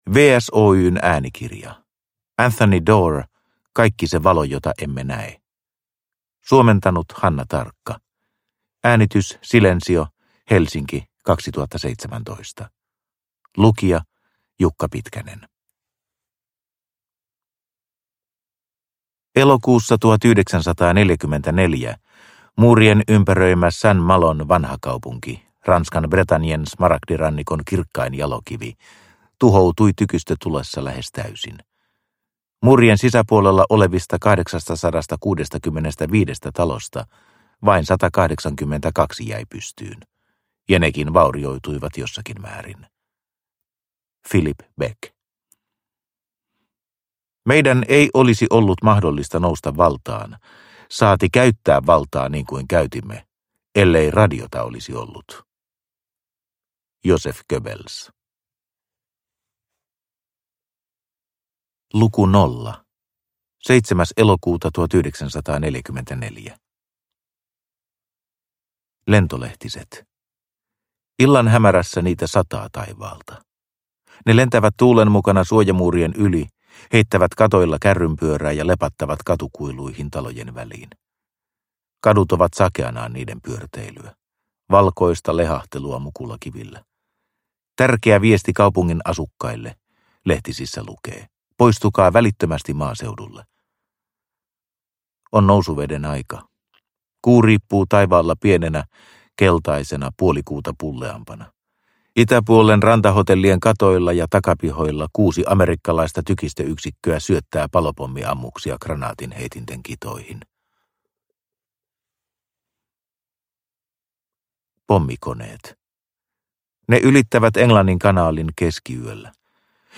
Kaikki se valo jota emme näe – Ljudbok – Laddas ner